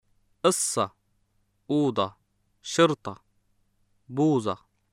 ص،ض،ط،ظ S,D,T,Z の後に ـة がついているときも -a と発音します。
[ʔəSSa, ʔuuDa, ʃərTa, buuZa]